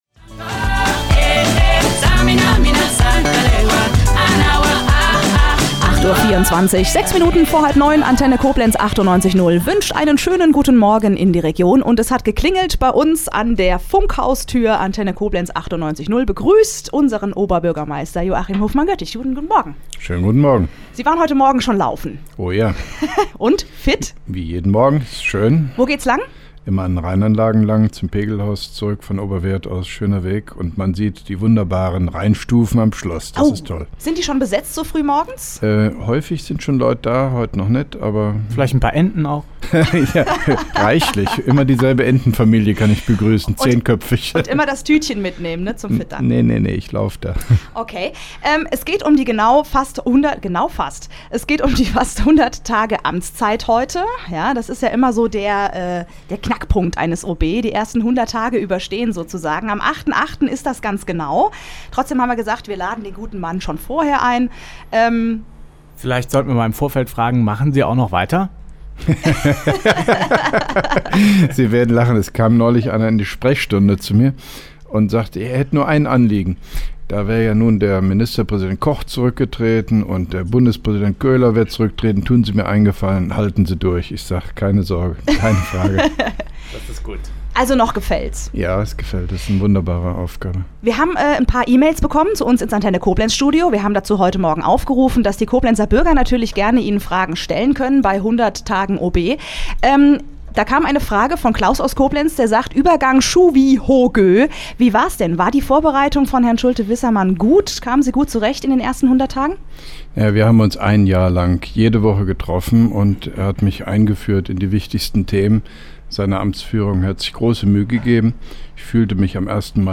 (1) Rundfunk-Interview mit dem Koblenzer OB Joachim Hofmann-Göttig zu den ersten 100 Tagen als Oberbürgermeister am 08.08.2010: